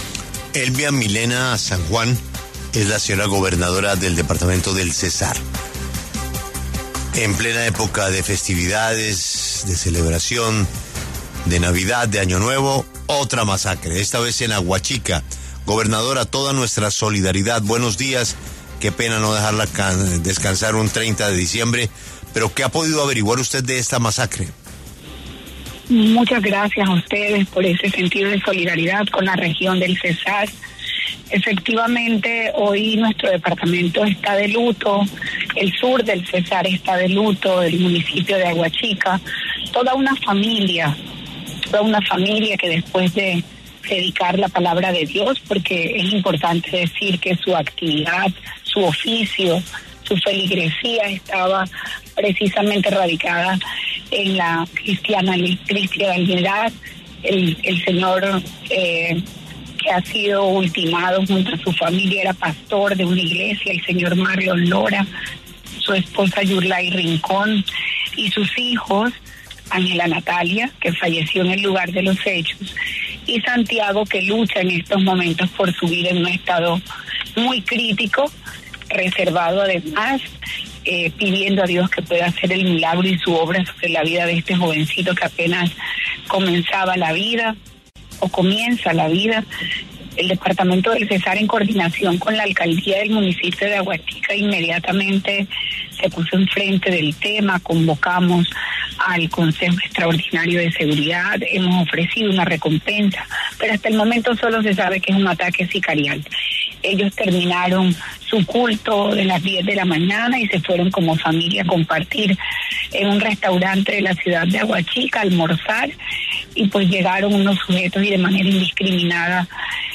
Elvia Sanjuan, gobernadora del Cesar, se refirió en La W a la masacre en Aguachica en la que cuatro integrantes de una familia fueron atacados y tres de ellos murieron.